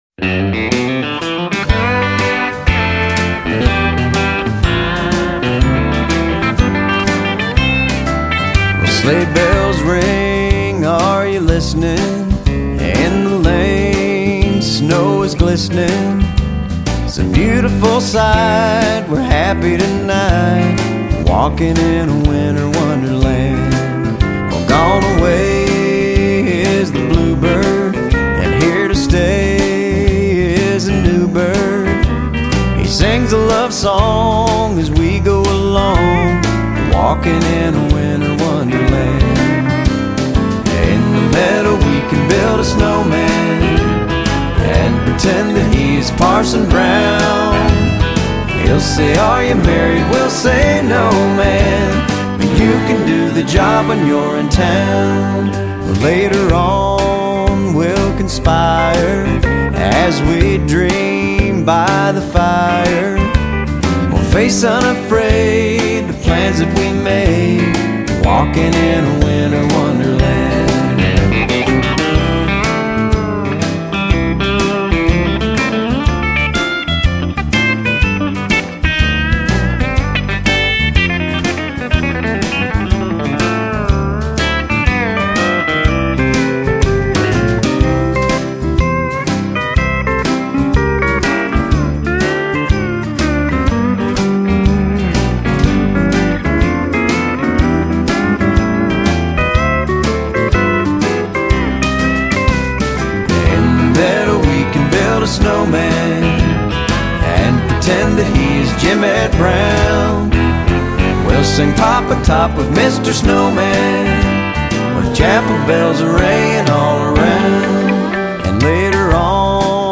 音乐类型：Country